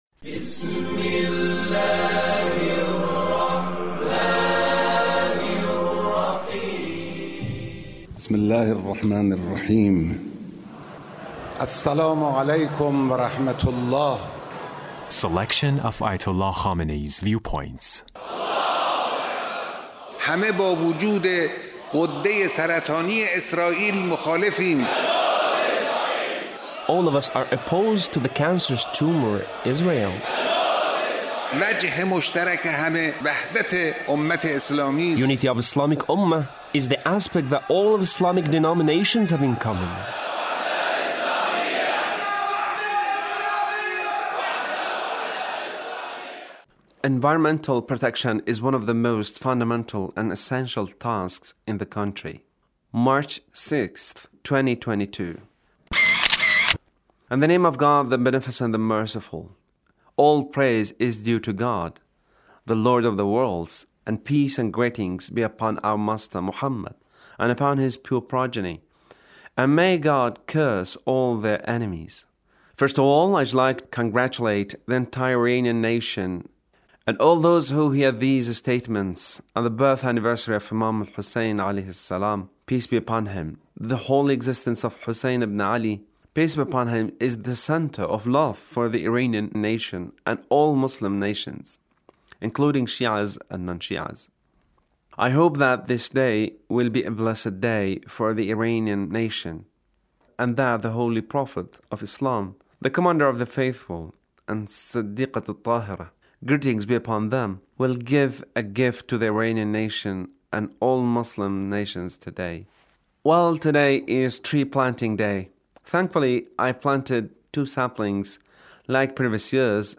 Leader's speech (1368)